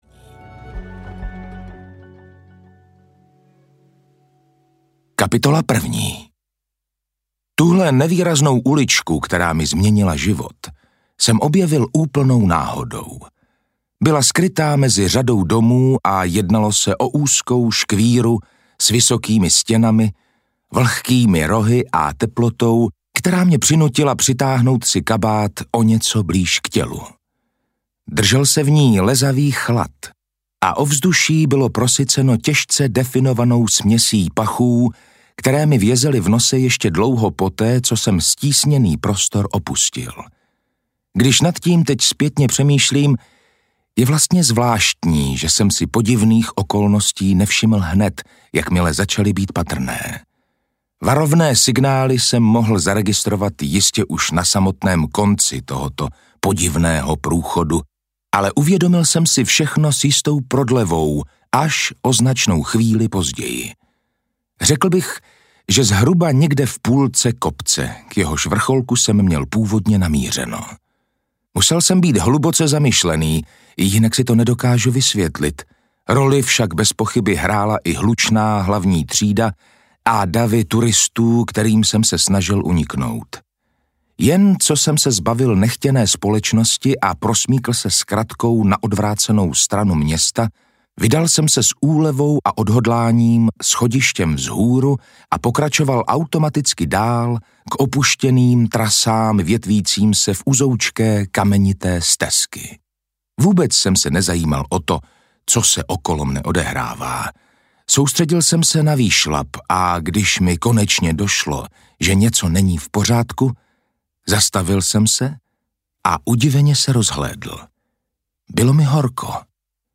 Postranní ulička audiokniha
Ukázka z knihy
postranni-ulicka-audiokniha